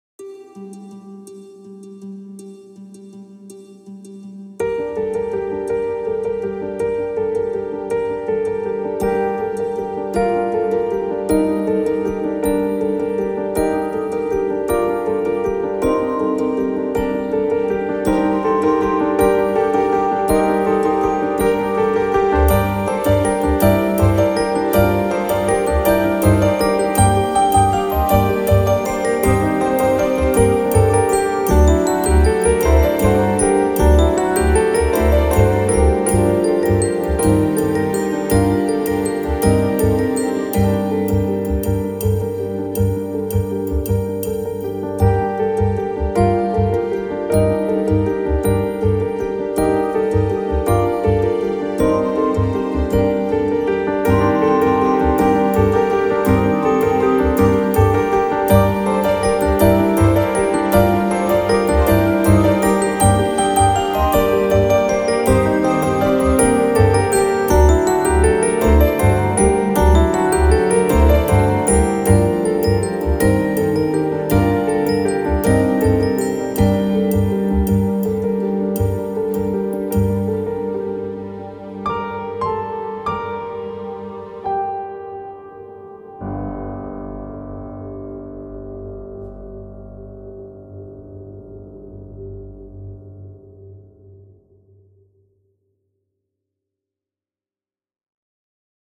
Carol of the Bells (Instrumental) | Ipswich Hospital Community Choir